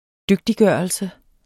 Udtale [ -ˌgɶˀʌlsə ]